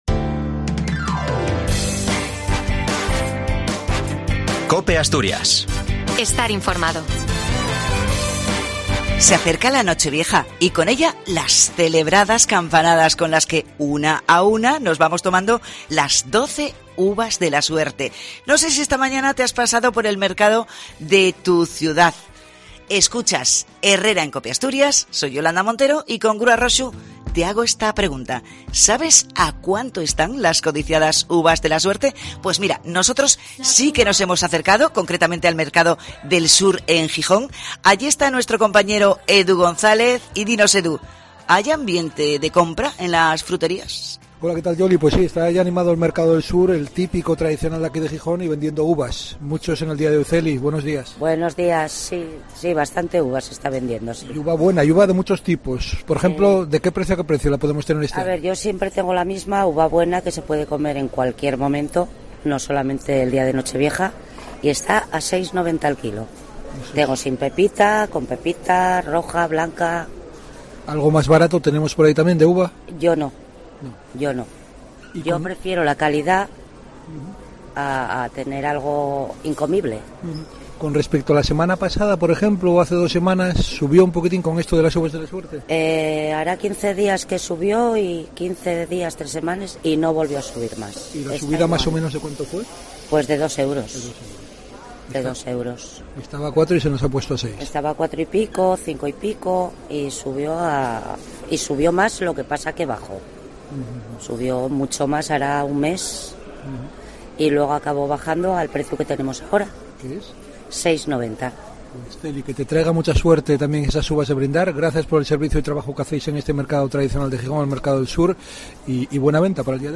Hemos estado en el tradicional Mercado del Sur de Gijón y te acercamos los precios de los manjares tradicionales para la última cena del año, con el postre estrella incluido